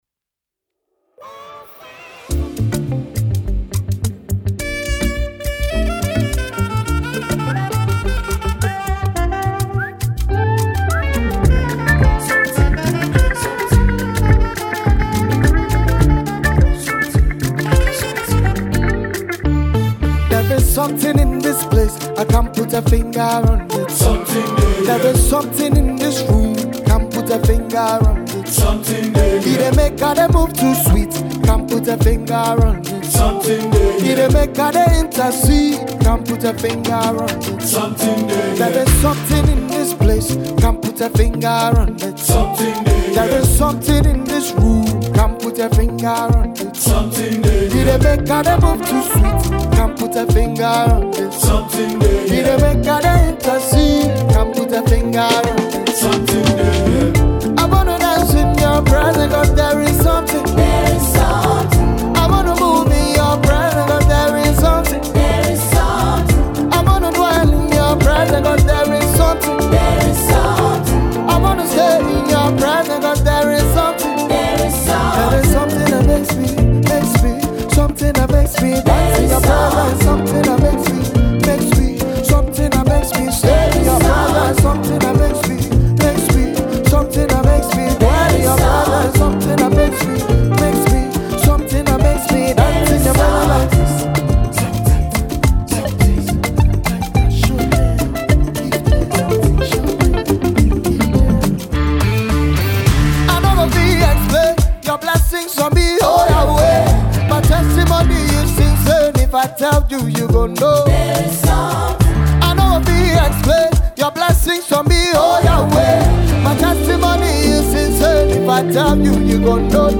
a Ghanaian gospel singer